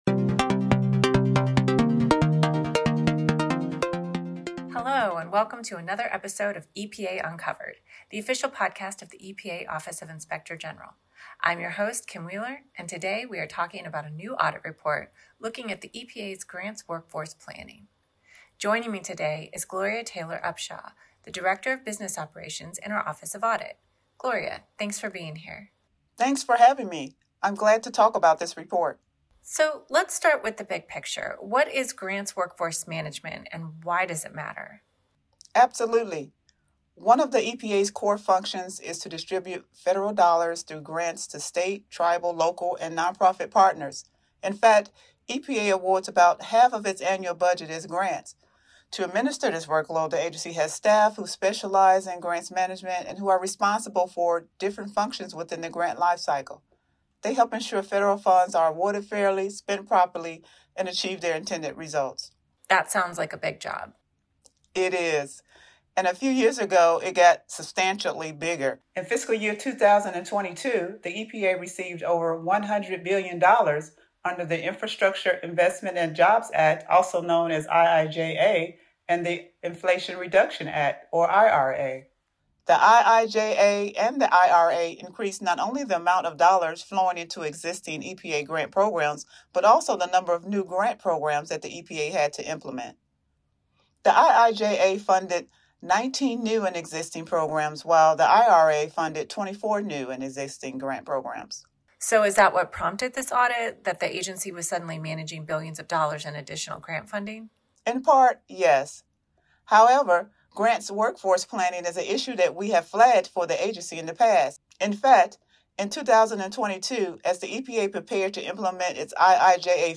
Podcast: Interview